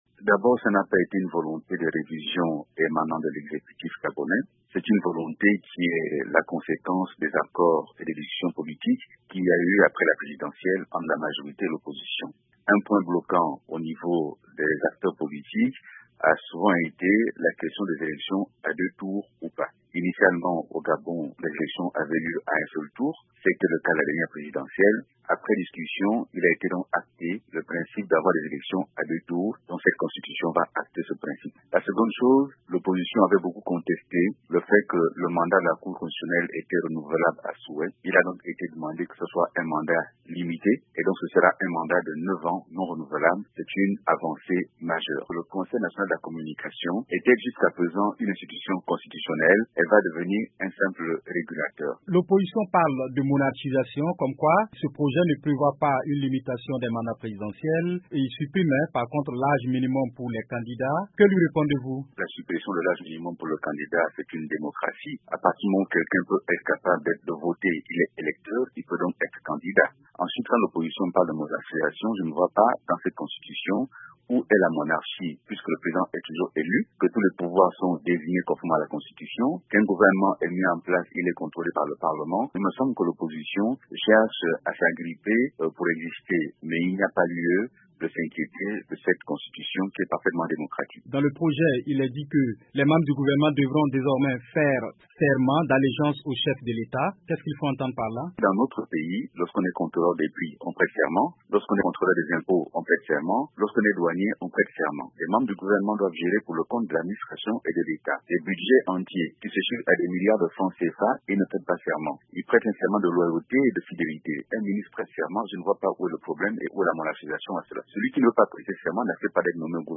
Alain Claude Billie By Nzé, porte-parole du gouvernement